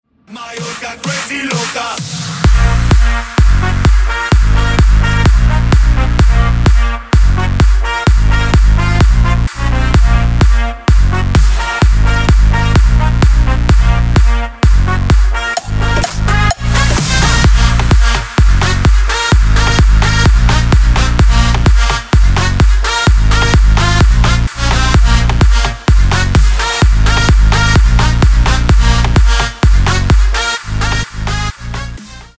dance
Electronic
EDM
Club House
electro house